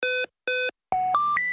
короткие
гудки
электронные